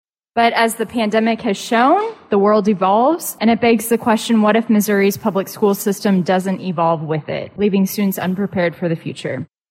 Senator Lauren Arthur of Kansas City sponsors  Senate Bill 662, which would later be combined with Senate Bill 681:
Audio courtesy of the Missouri Senate.